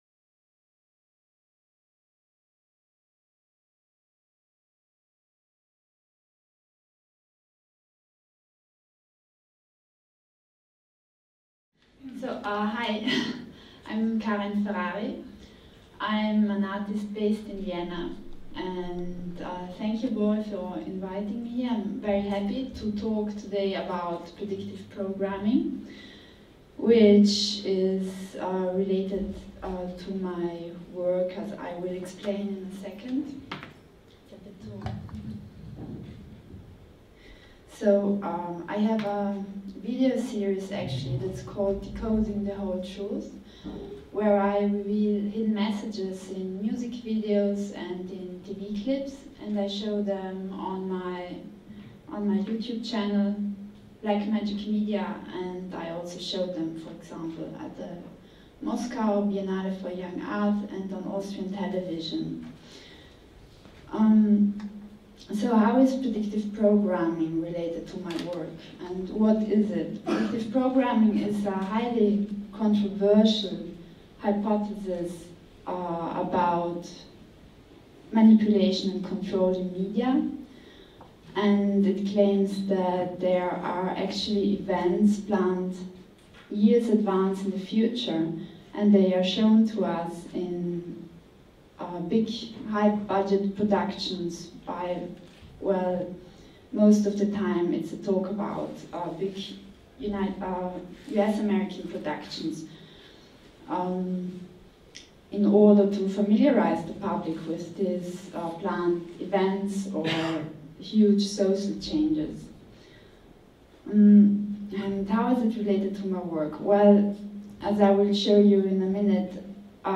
Lecture-performance series organized by Trafó Gallery / GONDOLAT PLASZTIKA.